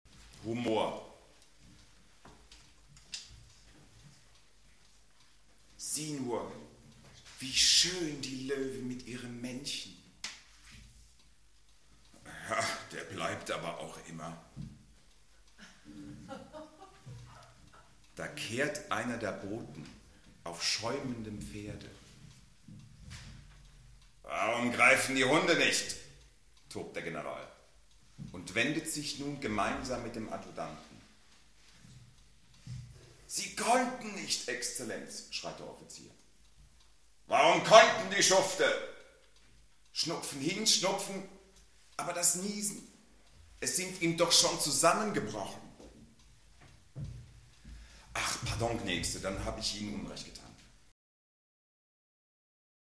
live bei act art 9 am 19. Juni 1999 in Zirndorf.